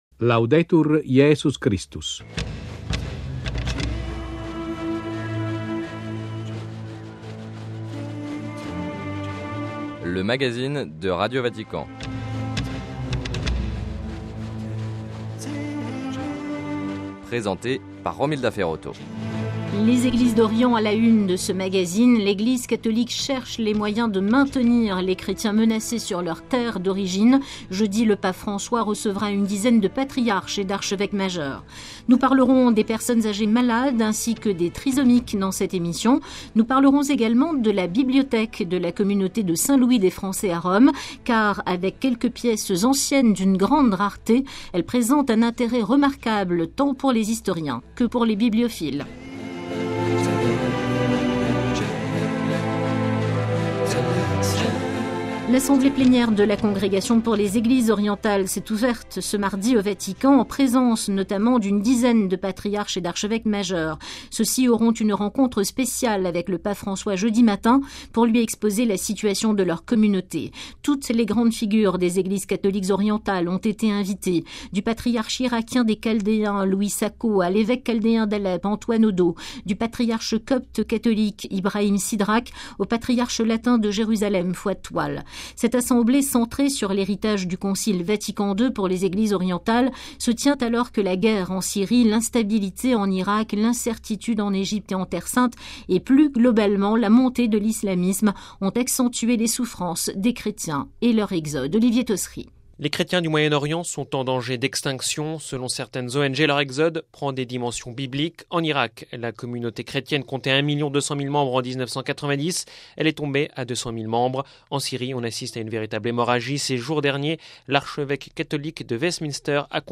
- Entretien avec l'archevêque catholique d'Alep, en Syrie, Mgr Jeanbart. - Conférence au Vatican sur les personnes âgées malades, atteintes de pathologies neurovégétatives.